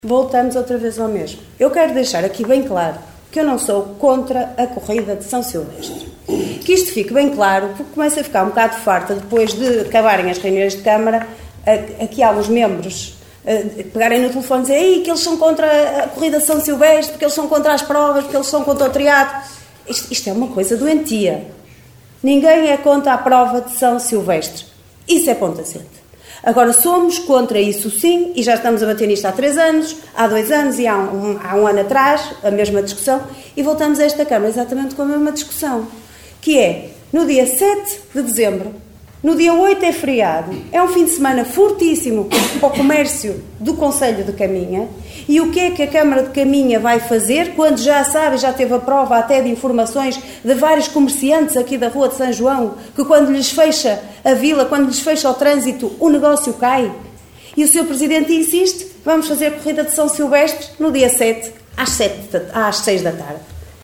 A vereadora da oposição, Liliana Silva, diz que esta situação é mais do mesmo. Não sendo contra a realização da prova, a eleita da Coligação o Concelho em Primeiro considera que a Câmara e a organização da corrida podiam escolher outra data e outro horário para a sua realização, até porque conhecem bem as dificuldades e os constrangimentos que a mesma causa aos comerciantes.